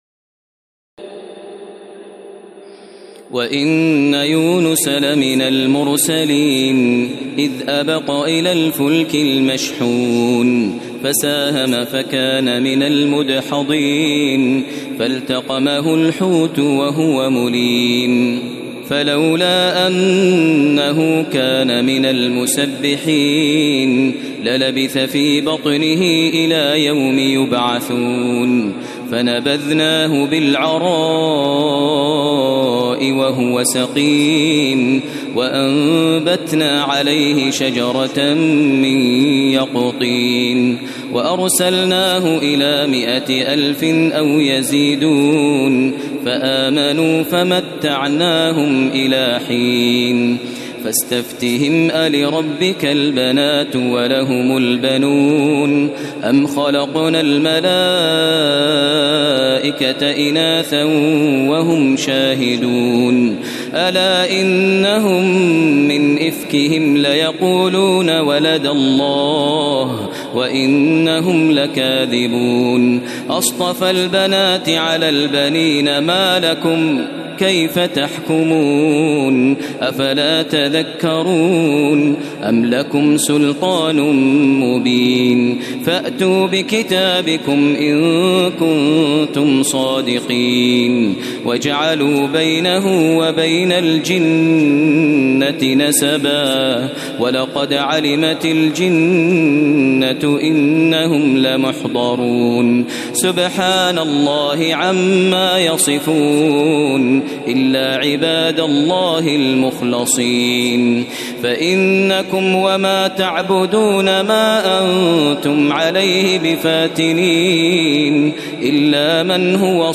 تراويح ليلة 22 رمضان 1432هـ من سور الصافات (139-182) وص و الزمر (1-31) Taraweeh 22 st night Ramadan 1432H from Surah As-Saaffaat and Saad and Az-Zumar > تراويح الحرم المكي عام 1432 🕋 > التراويح - تلاوات الحرمين